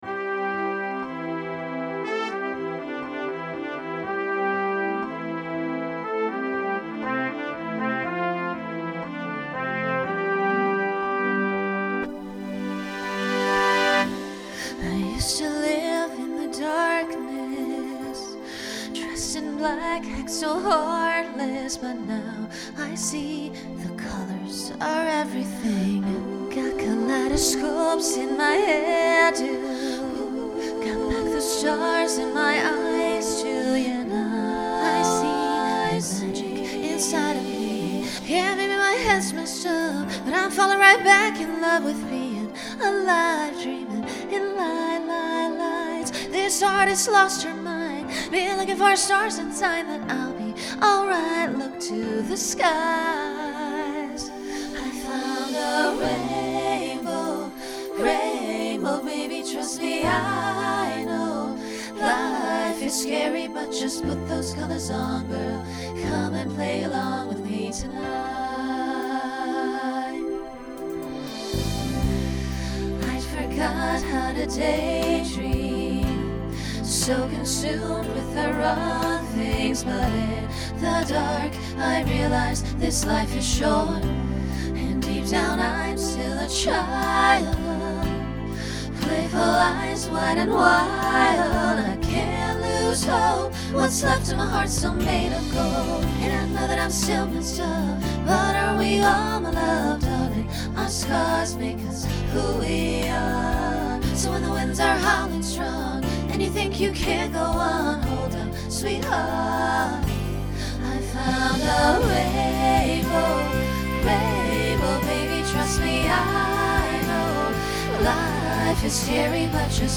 Genre Pop/Dance Instrumental combo
Function Ballad Voicing SAB